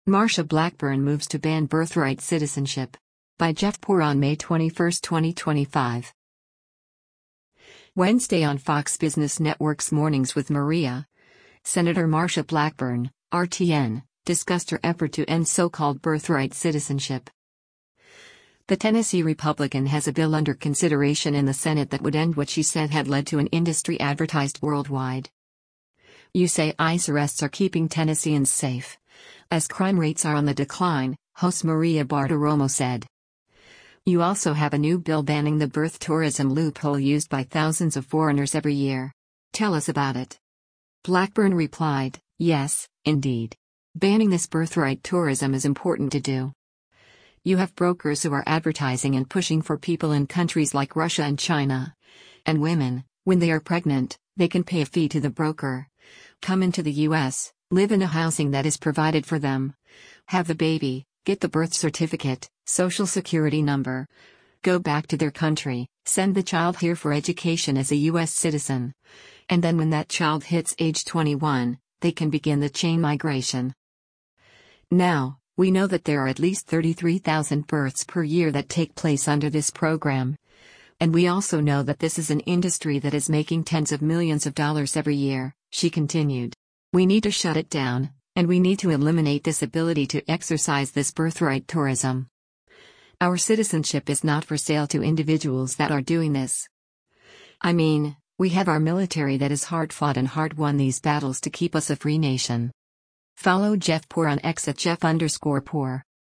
Wednesday on Fox Business Network’s “Mornings with Maria,” Sen. Marsha Blackburn (R-TN) discussed her effort to end so-called birthright citizenship.